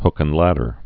(hkən-lădər)